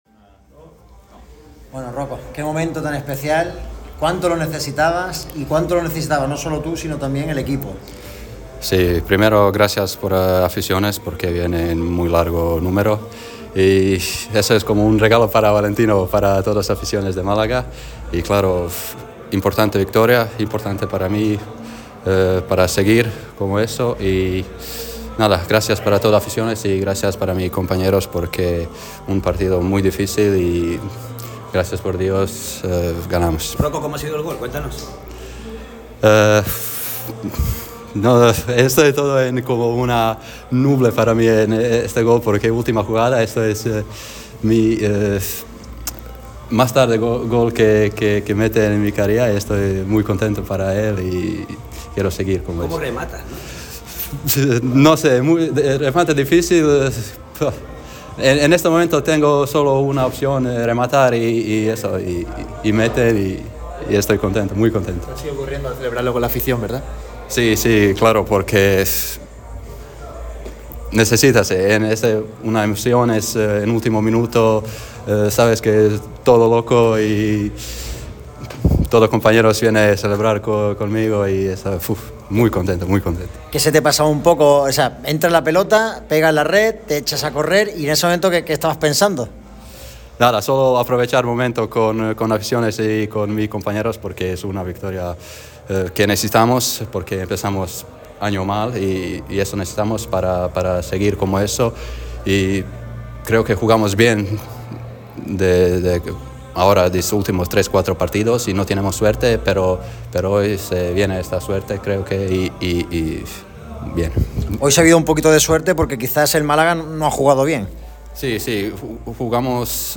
El delantero del Málaga CF, Roko Baturina, ha comparecido en zona mixta ante los medios tras un golazo en la última jugada para dar los tres puntos al Málaga en Cartagena. El croata ha valorado su remate acrobático, la celebración en comunión con la afición y la dedicatoria a Radio MARCA Málaga tars el tanto, tal y cómo prometió el miércoles.